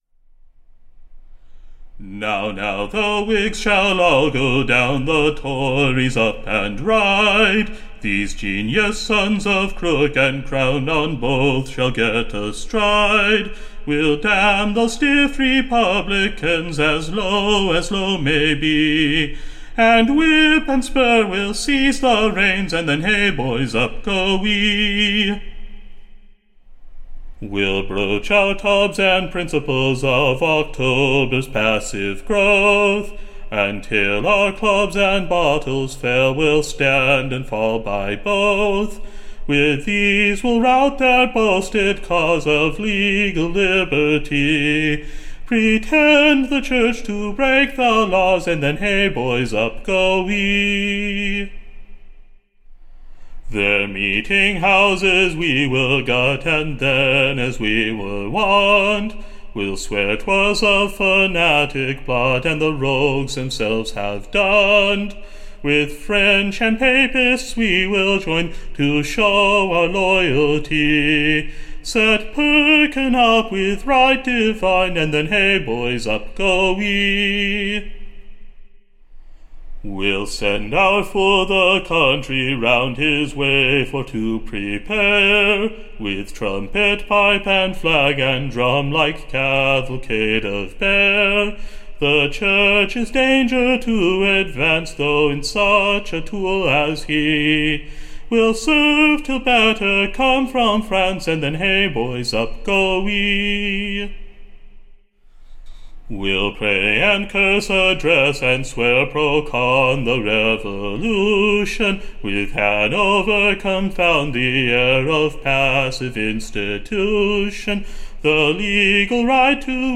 Recording Information Ballad Title The Tories Triumph / On the NEWS of / The Pretender's Expedition to Switzerland, / alias England.